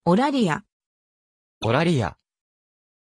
Aussprache von Eulalia
pronunciation-eulalia-ja.mp3